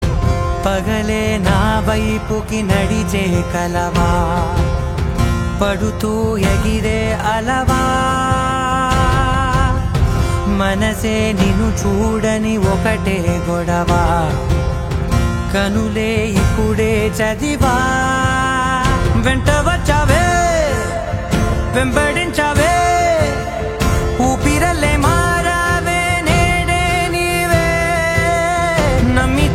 romantic love song
emotional voice